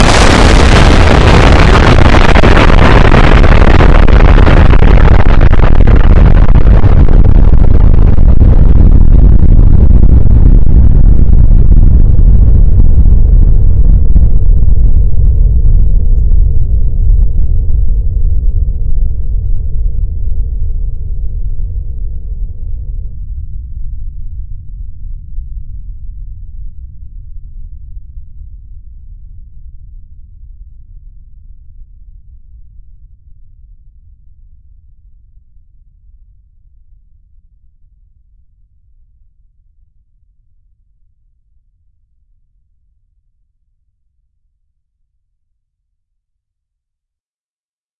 На этой странице собраны звуки работающих реакторов — от глухих гулов до мощных импульсов.
Грохот взрыва ядерного реактора